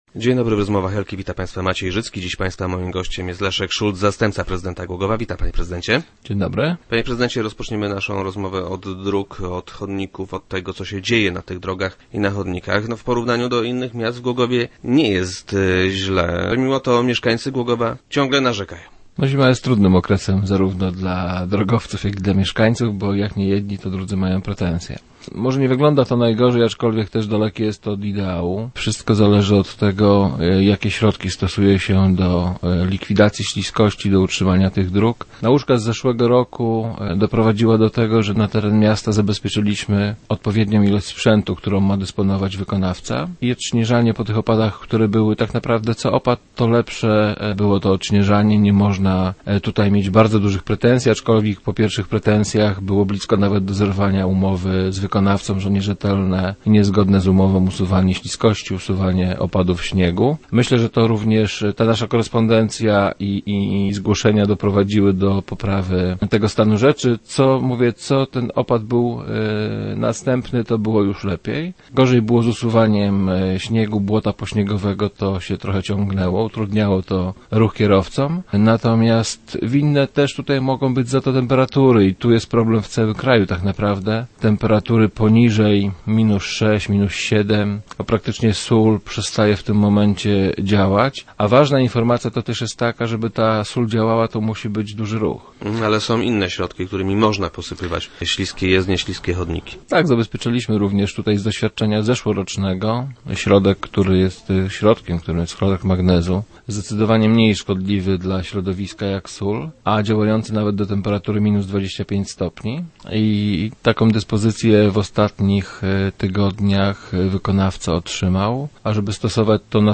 Zdaniem wiceprezydenta Leszka Szulca – nie najgorzej, ale nie obyło się bez problemów. Gościem Rozmów Elki był Leszek Szulc, zastępca prezydenta miasta.